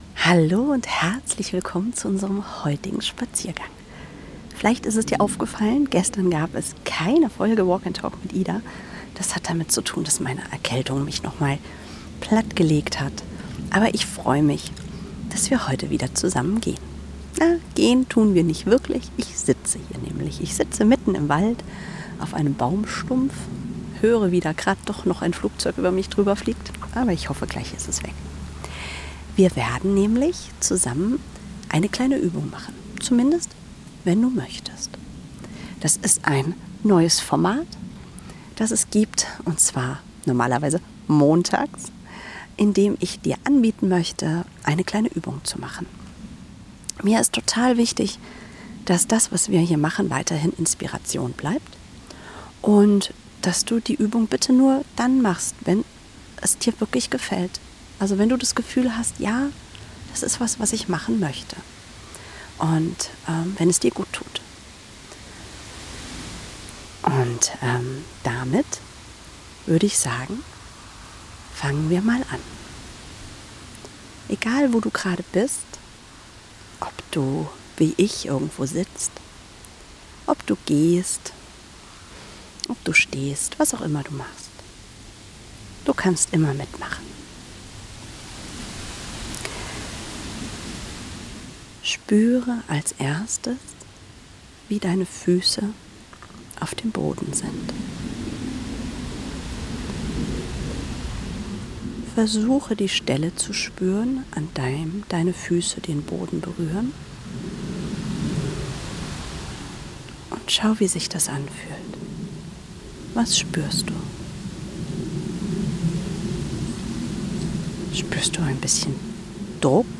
Ich sitze heute im Wald auf einem Baumstumpf und